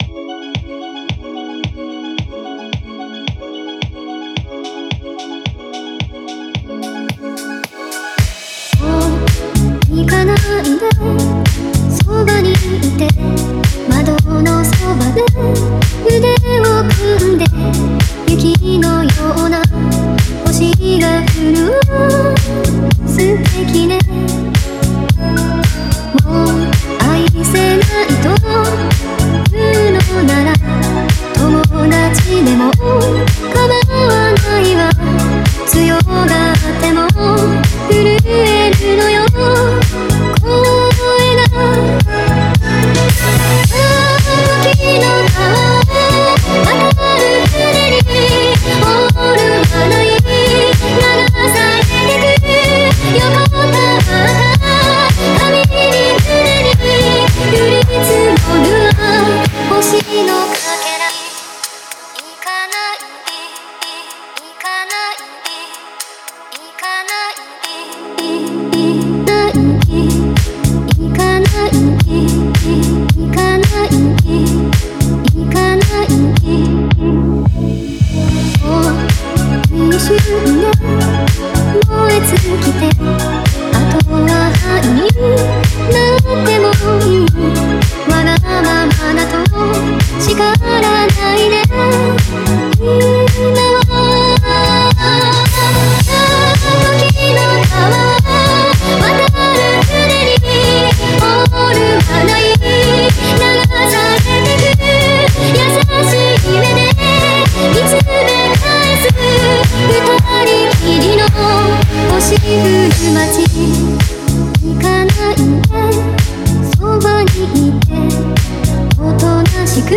Future Funk